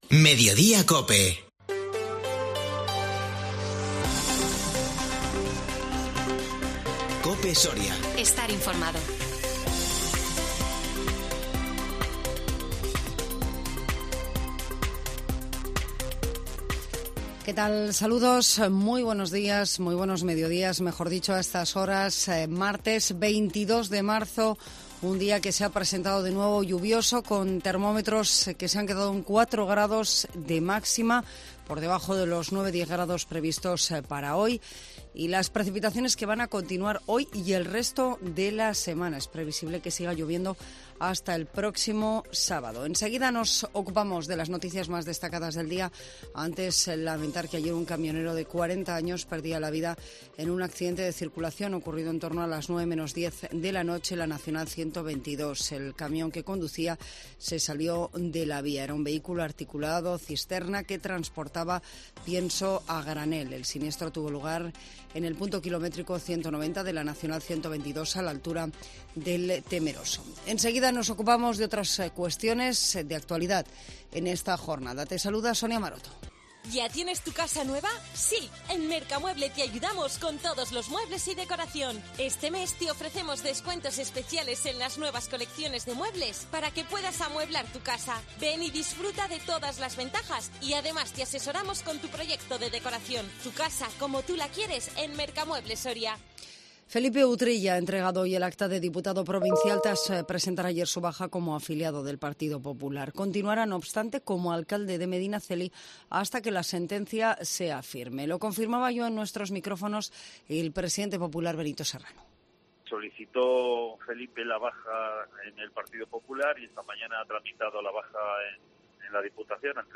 INFORMATIVO MEDIODÍA COPE SORIA 22 MARZO 2022